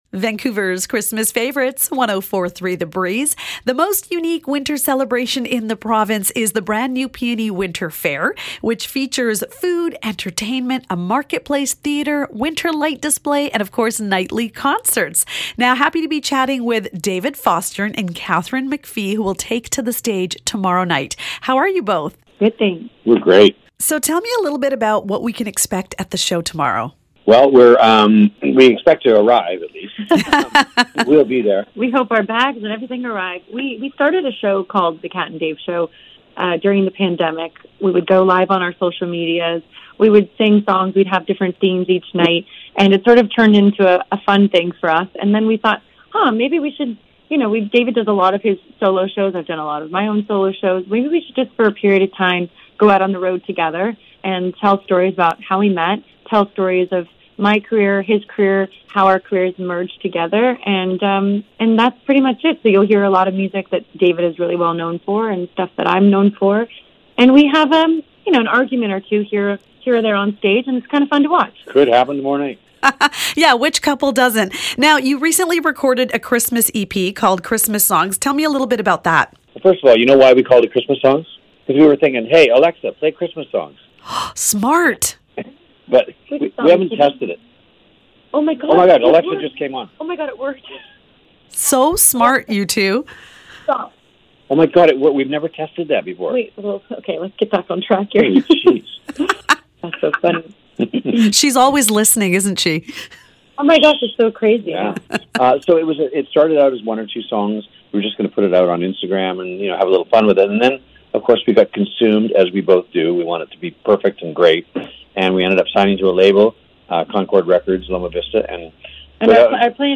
Interview with David Foster & Katharine McPhee: Performing Dec 14th PNE Winter Fair
Had a chance to chat with them before the show.